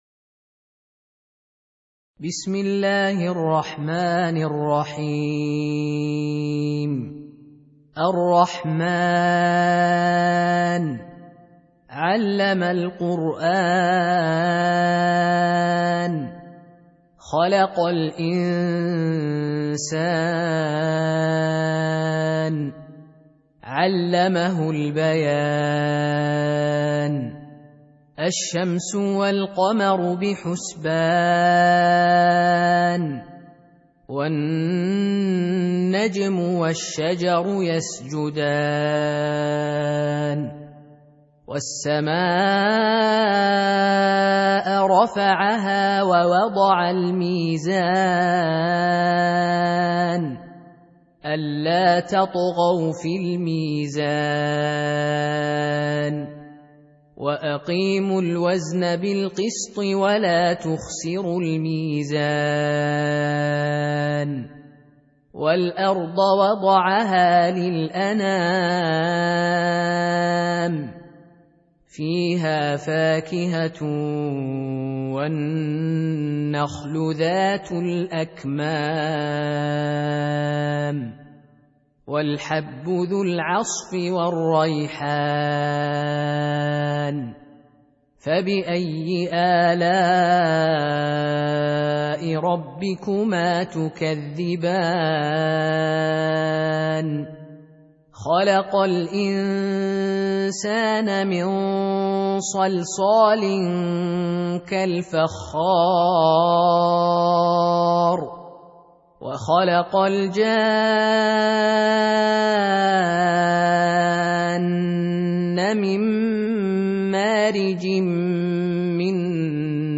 Surah Repeating تكرار السورة Download Surah حمّل السورة Reciting Murattalah Audio for 55. Surah Ar-Rahm�n سورة الرحمن N.B *Surah Includes Al-Basmalah Reciters Sequents تتابع التلاوات Reciters Repeats تكرار التلاوات